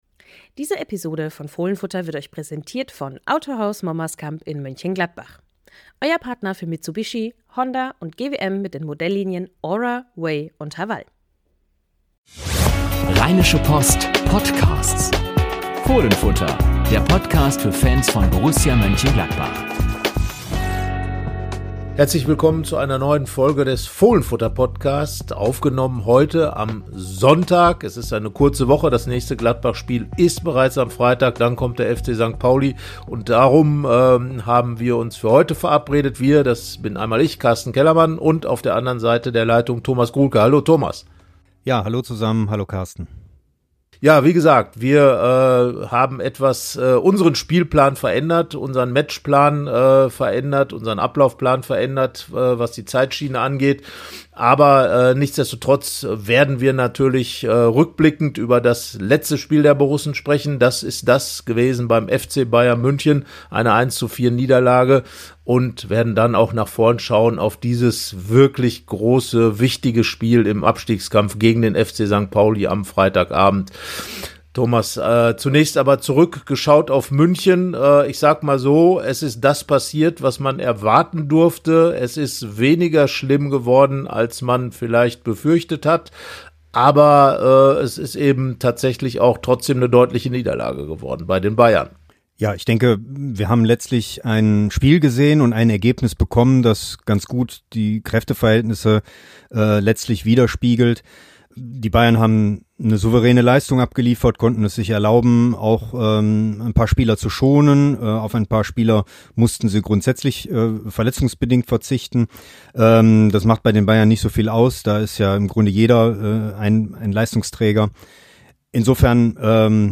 Borussia hat beim FC Bayern München die erwartete Niederlage kassiert. Was ärgerlich war an dem Abend, besprechen unsere Reporter im neuen Fohlenfutter-Podcast. Und sie stellen für das Abstiegsduell mit dem FC St. Pauli einige bemerkenswerte Personal-Ideen vor.